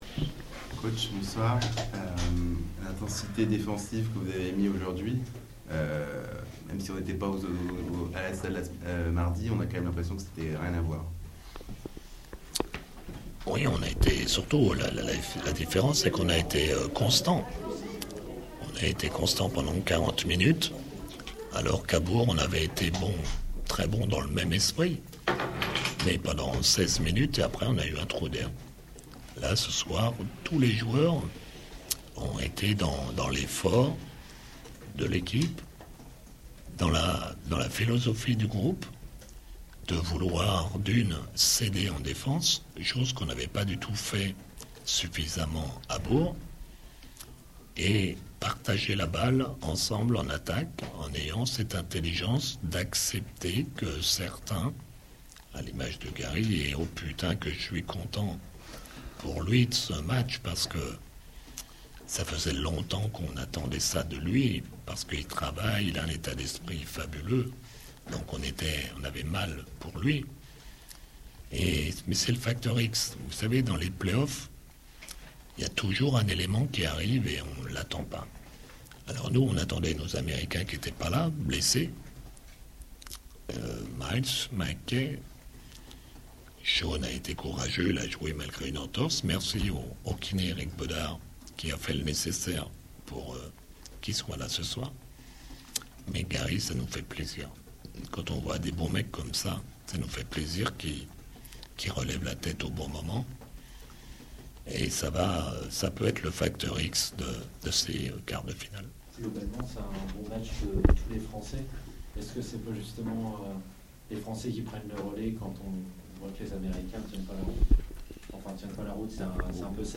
Interview d'après match - JL Bourg Basket